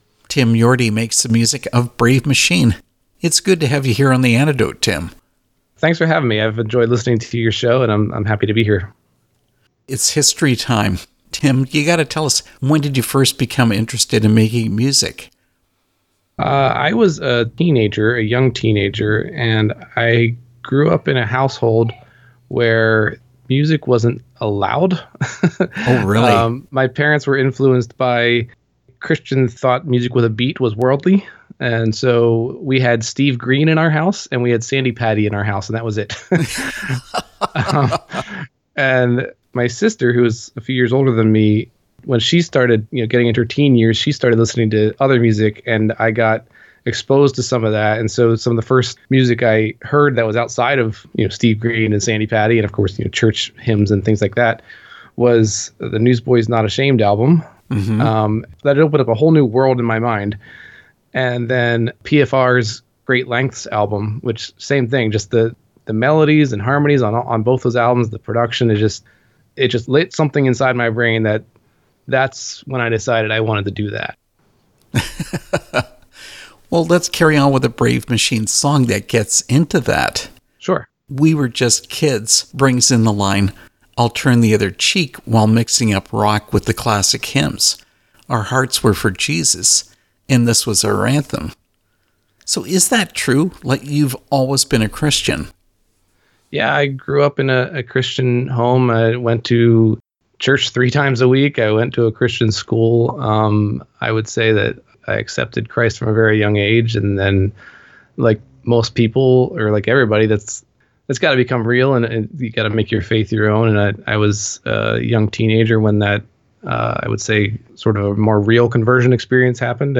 Brave Machine interview
brave-machine-interview.mp3